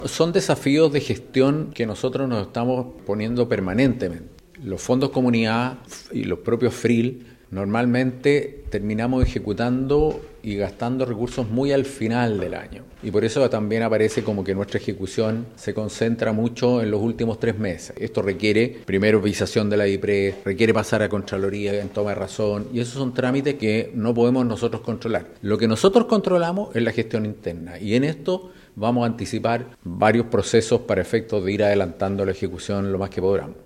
El gobernador subrogante, Carlos Recondo, explicó que se adoptarán medidas para mejorar la gestión interna y agilizar la tramitación de proyectos.
presupuesto-los-lagos-gobernador.mp3